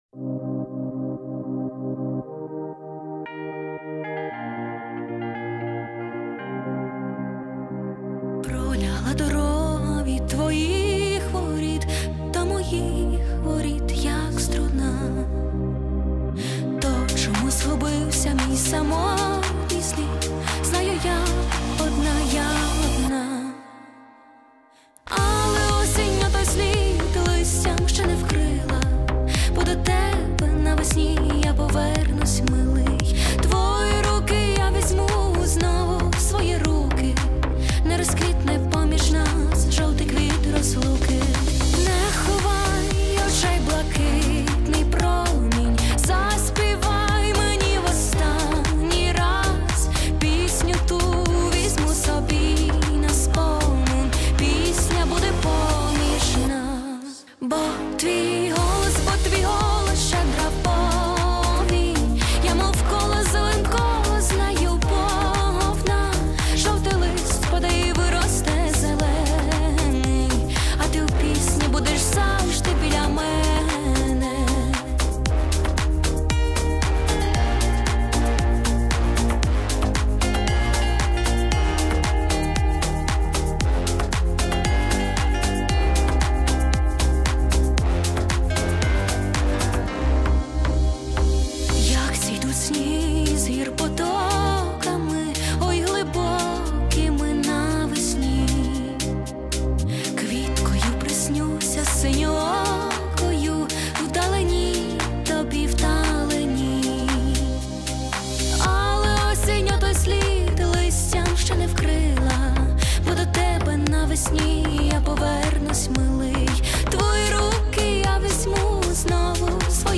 кавер cover